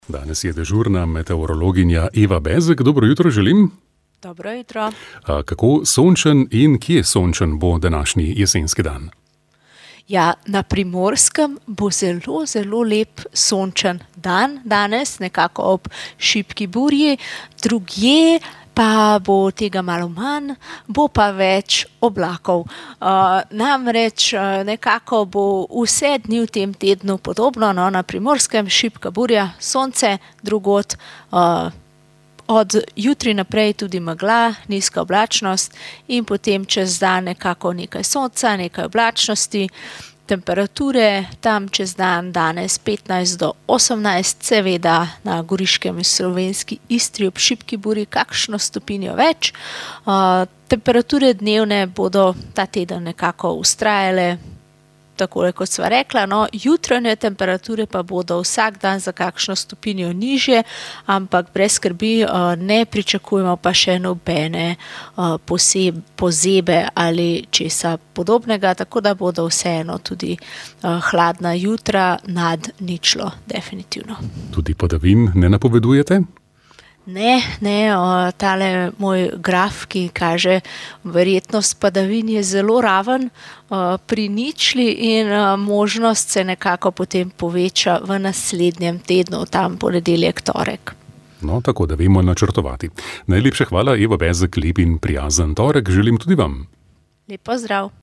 Sveta maša
Sv. maša iz stolne cerkve sv. Janeza Krstnika v Mariboru dne 31. 1.
Iz mariborske stolnice na četrto nedeljo med letom smo neposredno prenašali sveto mašo, ki jo je daroval mariborski nadškof Alojzij Cvikl.